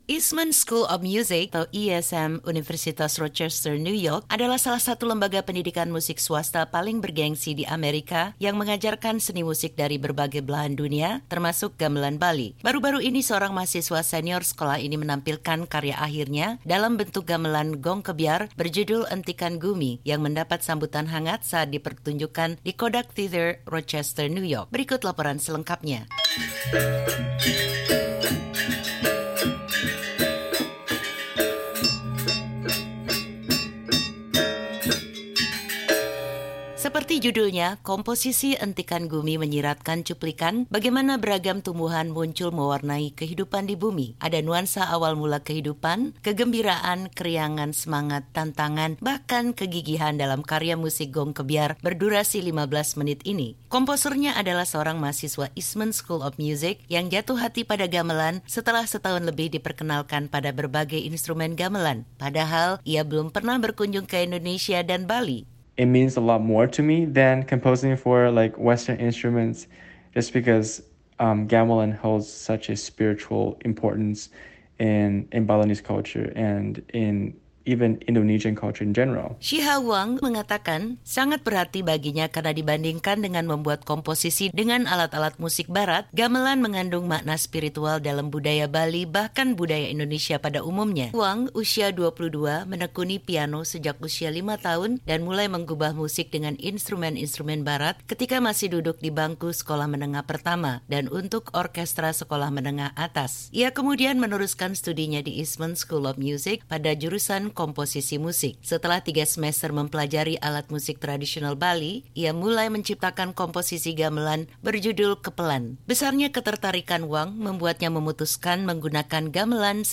New York baru-baru ini menampilkan karya akhirnya dalam bentuk gamelan gong kebyar berjudul “Entikan Gumi”